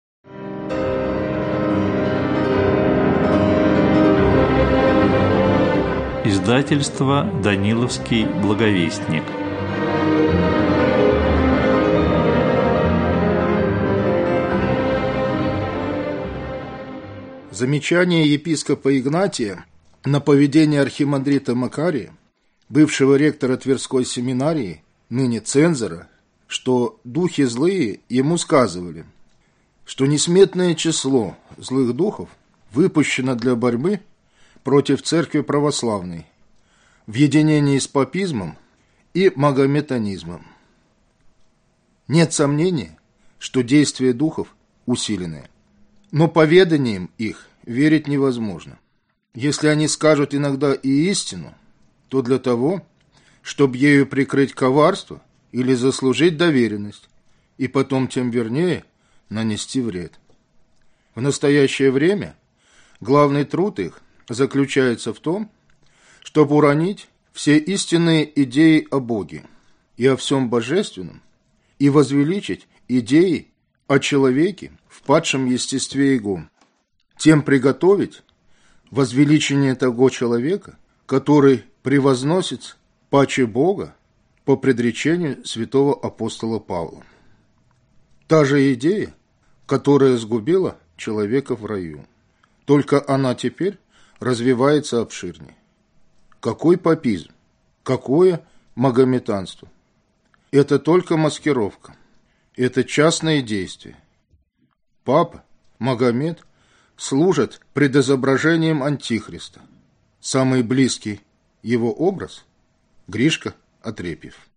Аудиокнига Письма 1 | Библиотека аудиокниг
Прослушать и бесплатно скачать фрагмент аудиокниги